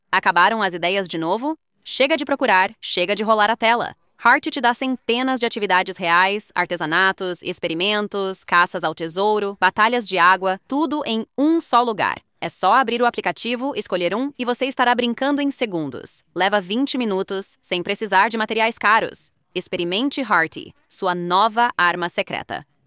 dubbed-pt.mp3